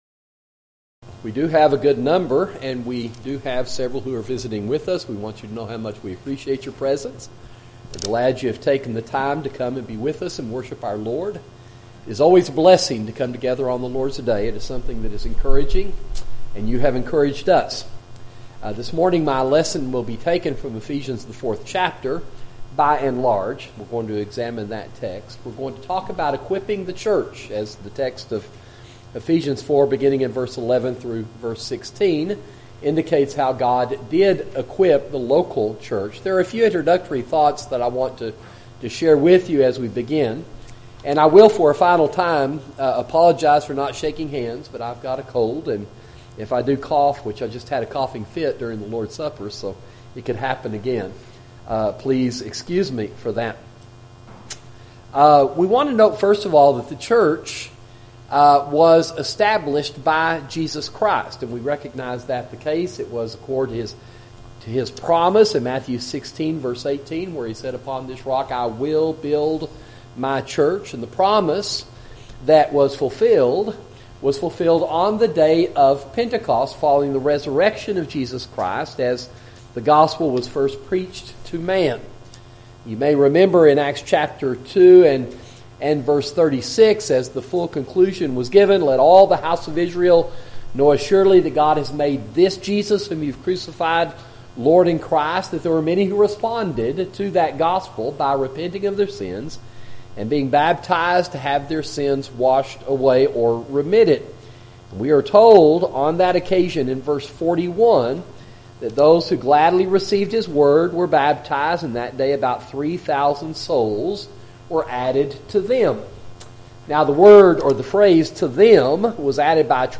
Sermons: Equipping the Local Church